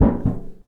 metal_tin_impacts_deep_02.wav